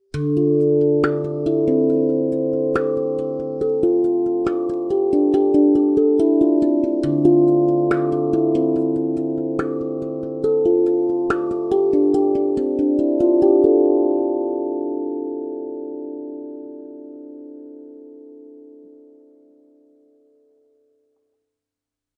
Rauschen bei Aufnahme meiner Zungentrommel (Shure SM7dB + Rodecaster Duo)
Das ist eigentlich zu nah und die enormen Obertöne scheinen nicht ideal eingefangen. Ich habe das mal durch eine Kette von Clarity Vx DeReverb Pro und Clraity Vx Rauschunterdrückung geschickt.
Ich finde das bringt dann den Charakter der Instruments noch mal deutlicher zur Geltung.
Leider ist in dem Sample keine Stelle wo die Raumgeräusche freistehen.